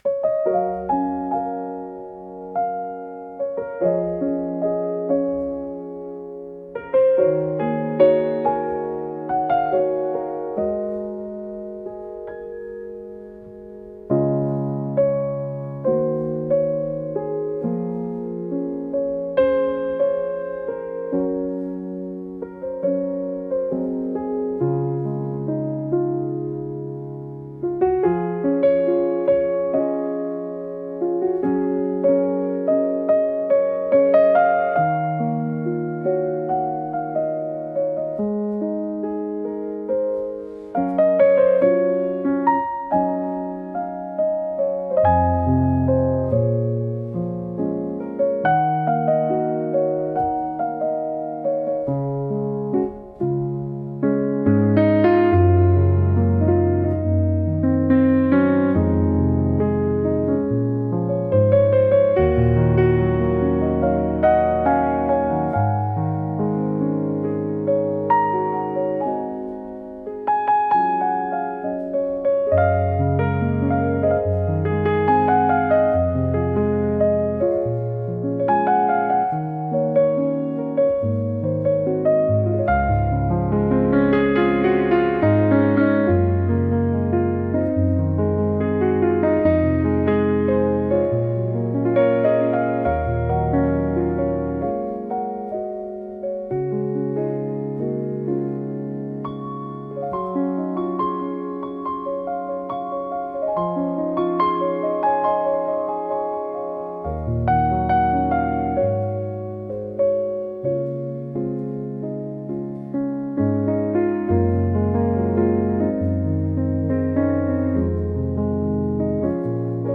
繊細で情感豊かな空気を醸し出すジャンルです。